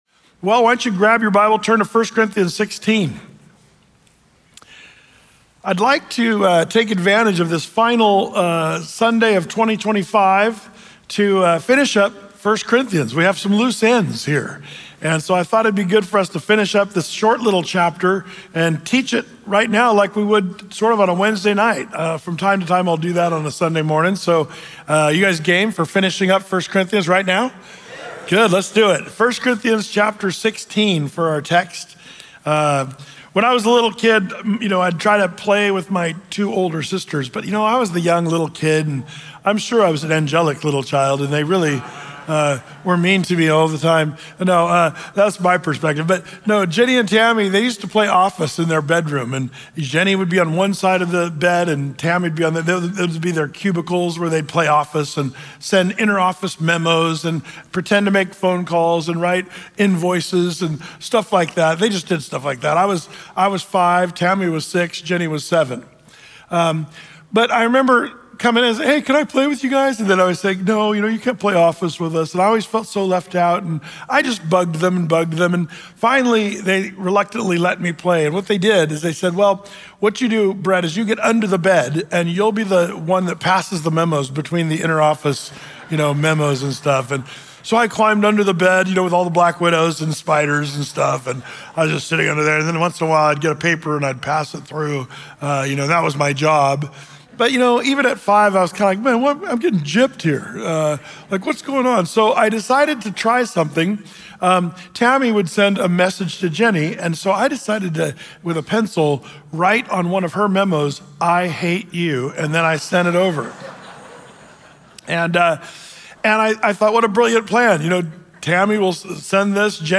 Through-the-Bible teaching